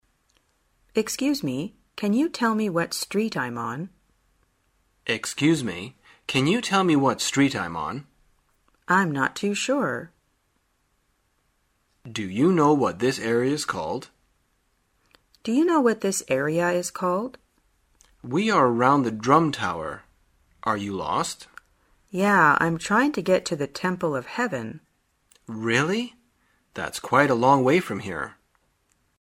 旅游口语情景对话 第328天:如何询问现在所处地点